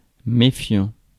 Ääntäminen
US : IPA : [səˈspɪʃ.əs]